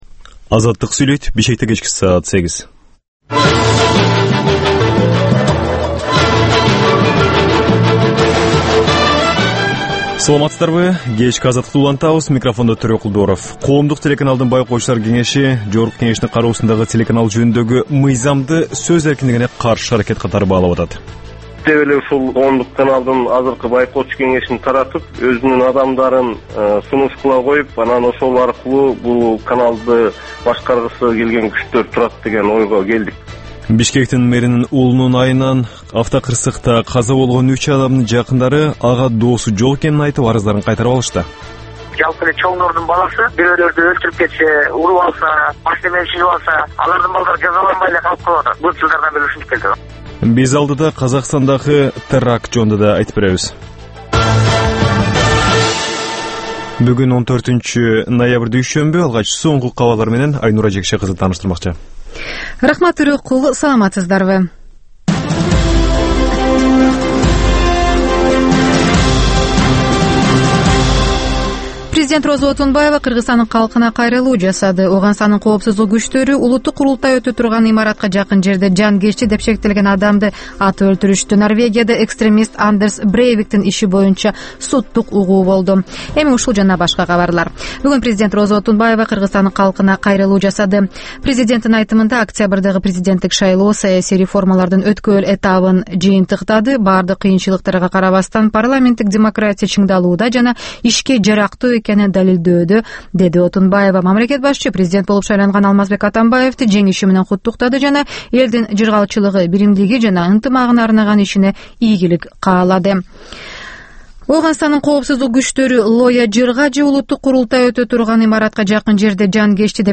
Кечки 8деги кабарлар